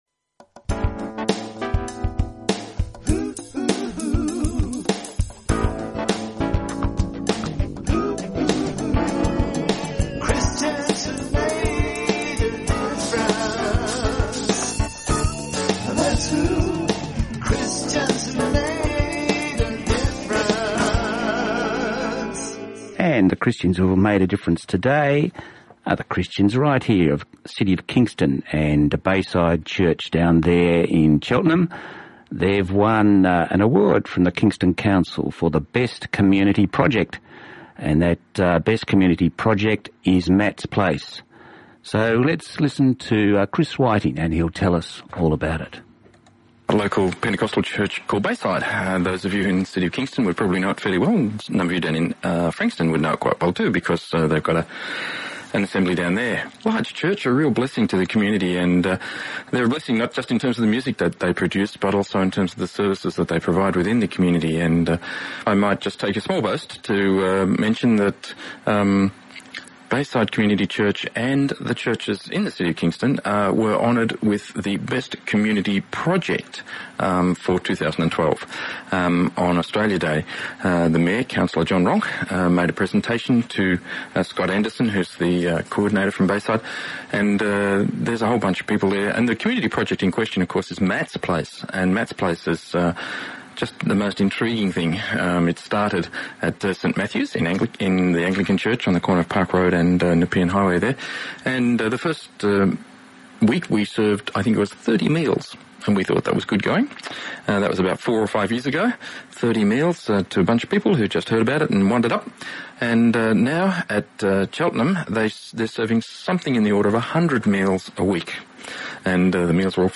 Hear the story broadcast on “Songs of Hope” on 29Oct17 on Southern FM 88.3.